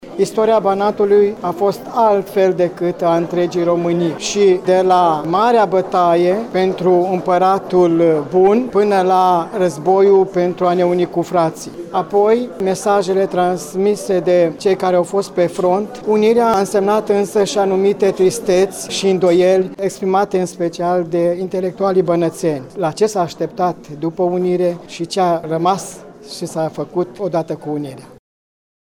O serie de dezbateri tematice având ca leitmotiv Prima mare conflagraţie mondială au conturat luni, 12 noiembrie la Universitatea „Eftimie Murgu” din municipiul de pe Bârzava, prima zi din seria de activităţi.